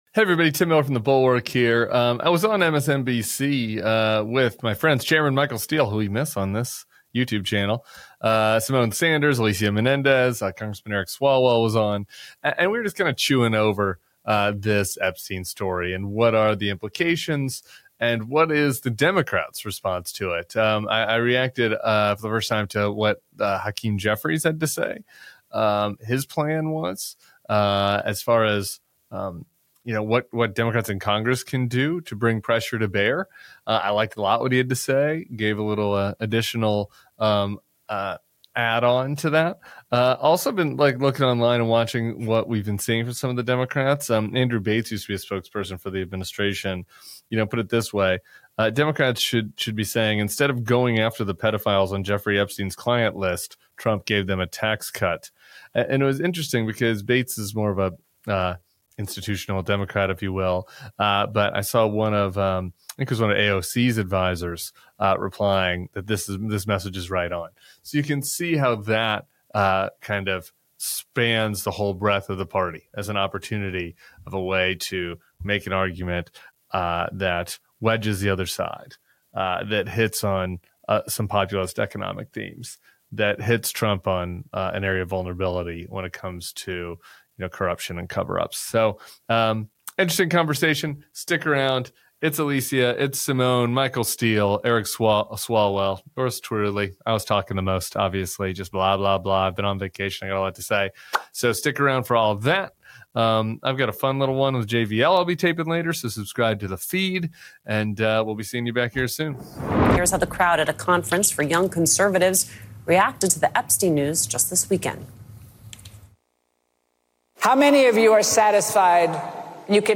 Tim Miller goes on MSNBC to break down how the Epstein story is turning into a real political vulnerability for Donald Trump, and why Democrats should lean into it.